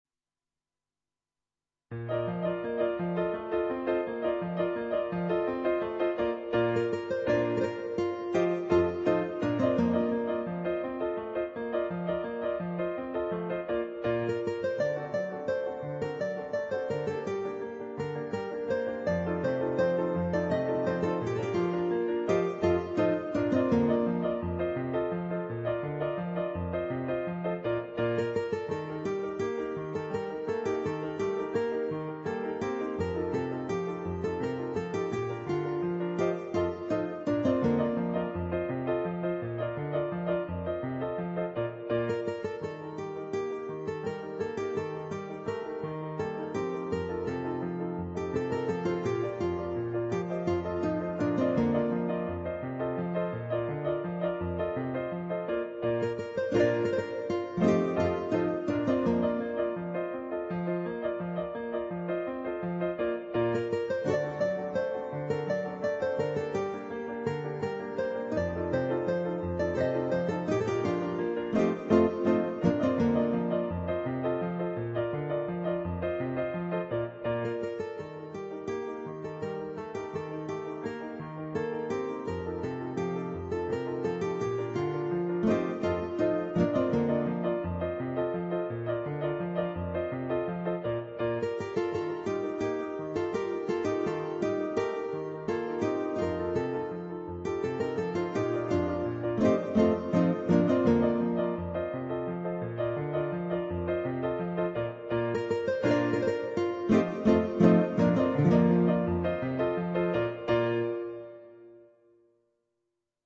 per chitarra e pianoforte
on Yamaha digital pianos.